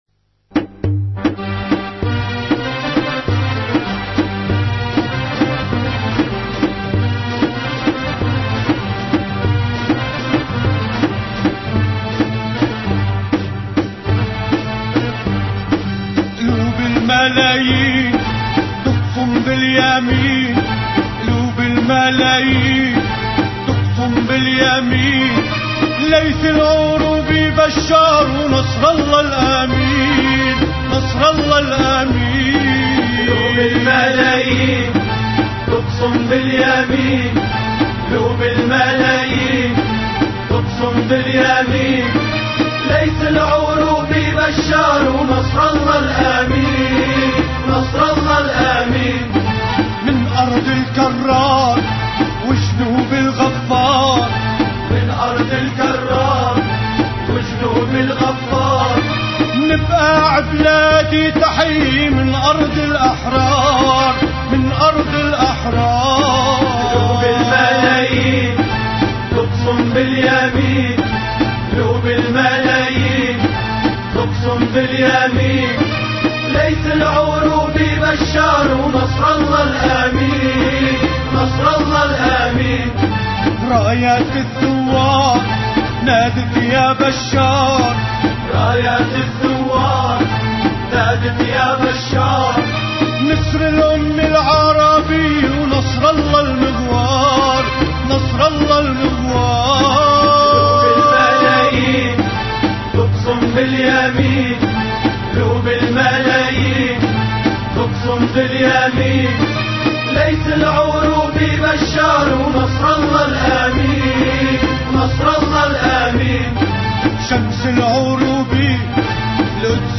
أناشيد لبنانية